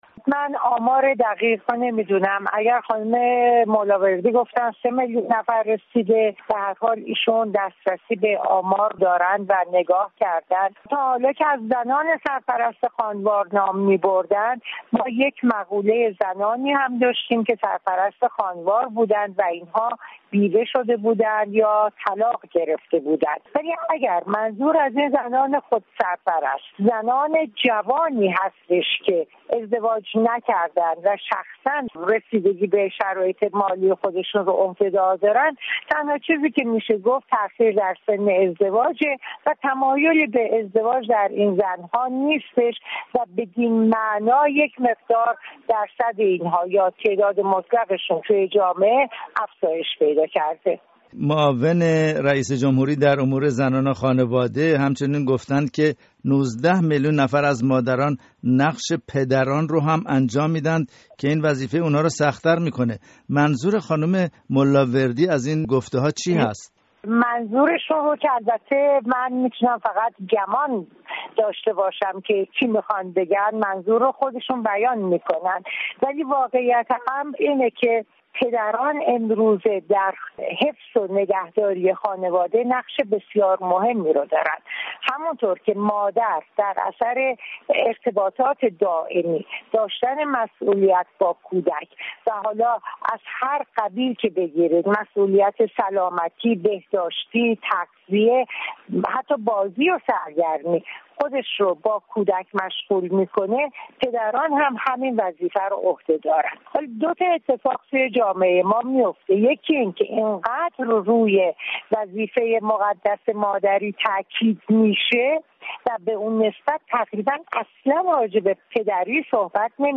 گفت وگوی رادیو فردا